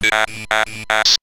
Index of /sp0256-tts/output